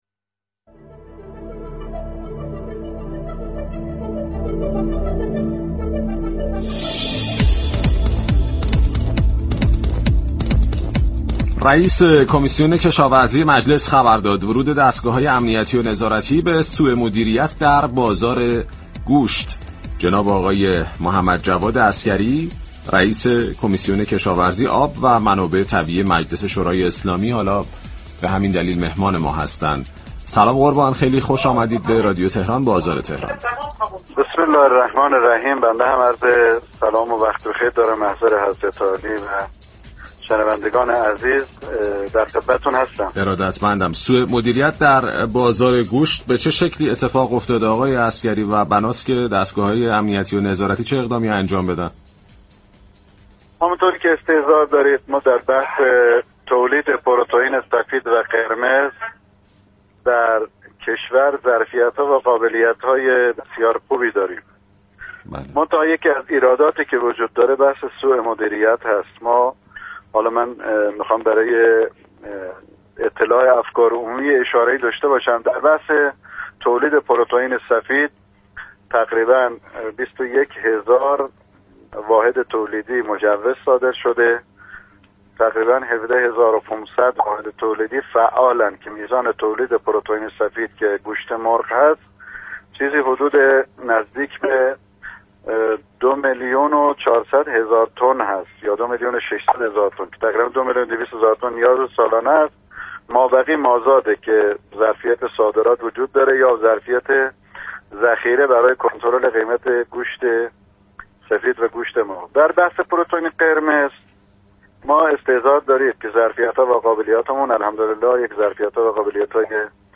رییس كمیسیون كشاورزی مجلس در گفت و گو با رادیو تهران از ورود نهادهای امنیتی و نظارتی به پرونده سوءمدیریت در تأمین نهاده‌های دامی و آشفتگی بازار گوشت خبر داد و تأكید كرد وزارت جهاد كشاورزی با وجود بودجه و اختیارات كافی، نتوانسته ذخایر استراتژیك كشور را مدیریت كند.